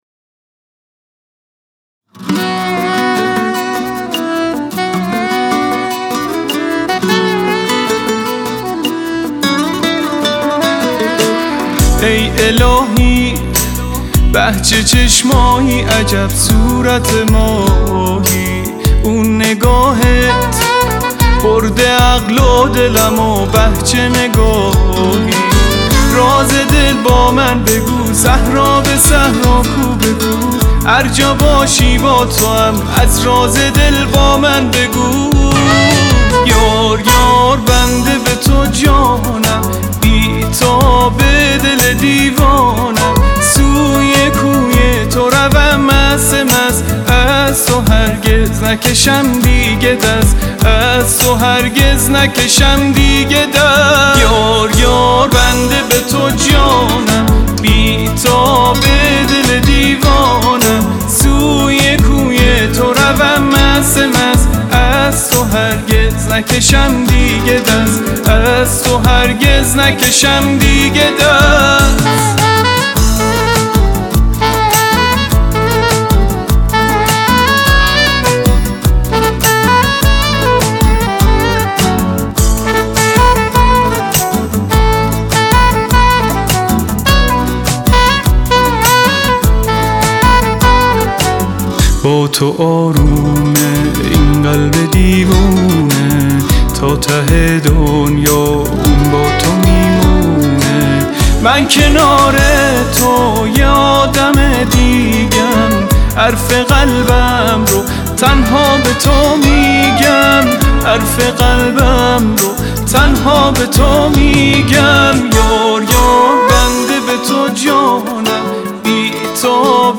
ساکسیفون